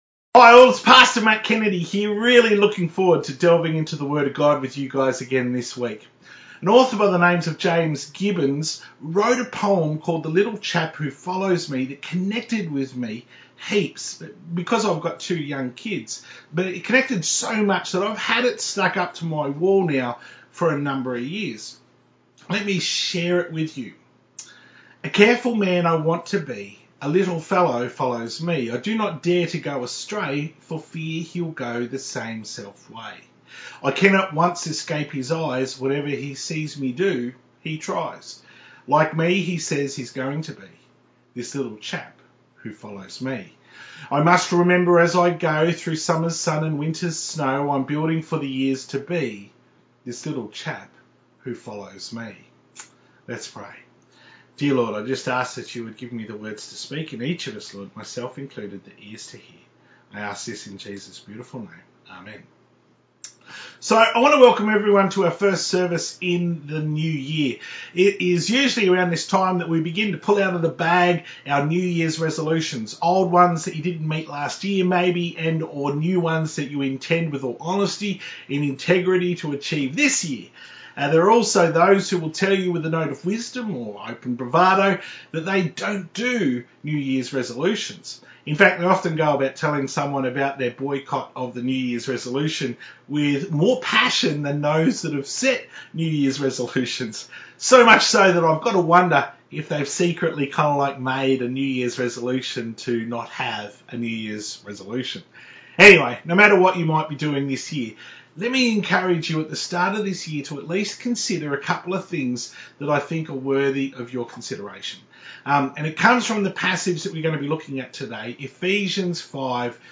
Ephesians 5:1-21 New Years message